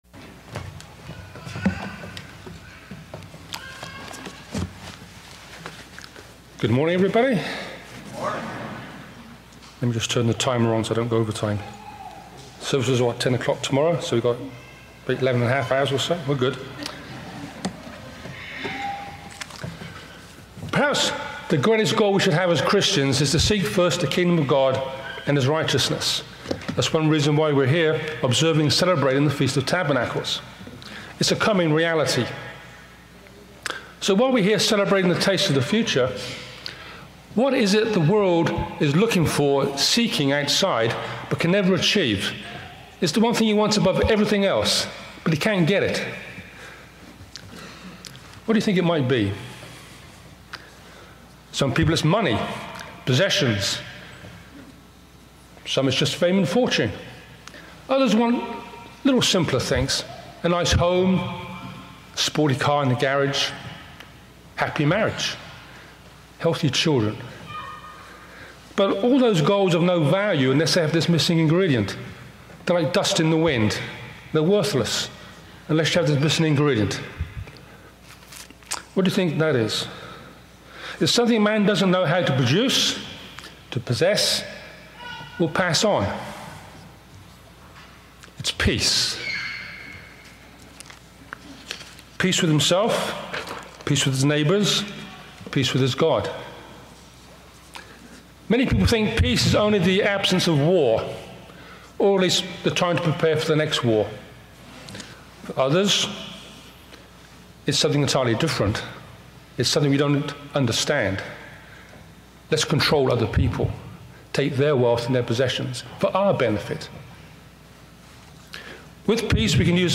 This sermon was given at the Lancaster, Pennsylvania 2021 Feast site.